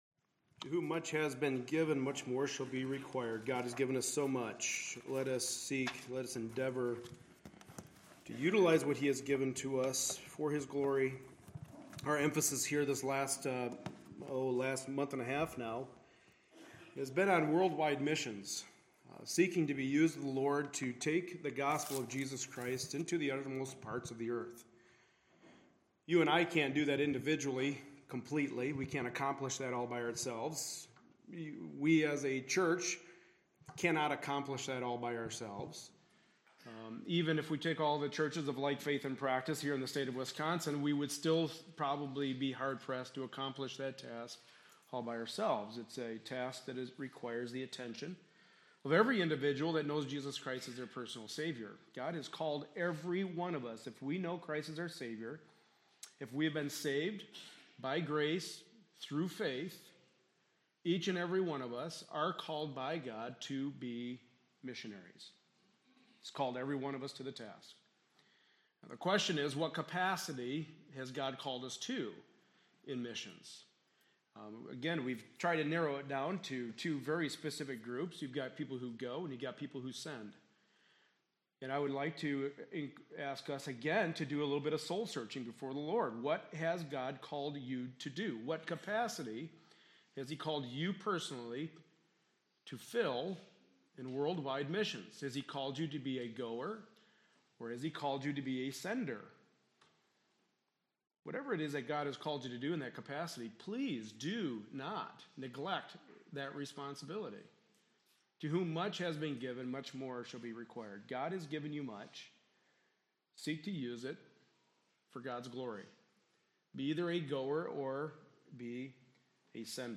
Passage: 2 Corinthians 11-12 Service Type: Sunday Morning Service